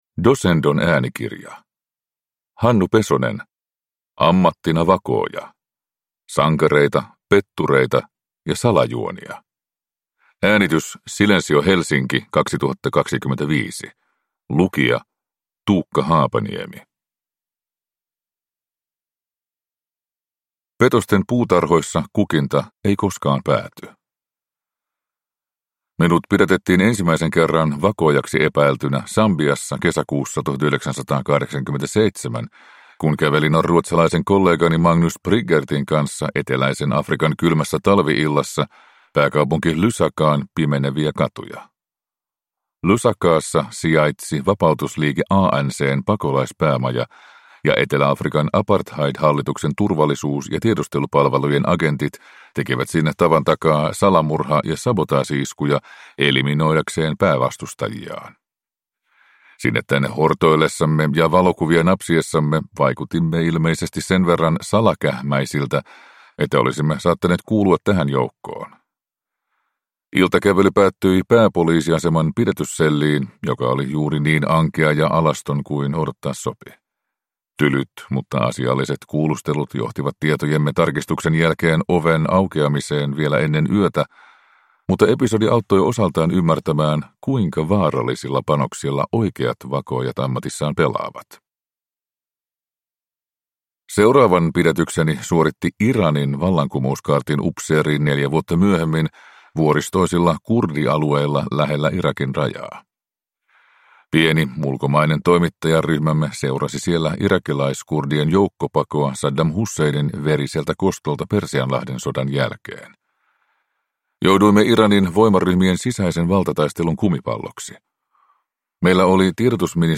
Ammattina vakooja (ljudbok) av Hannu Pesonen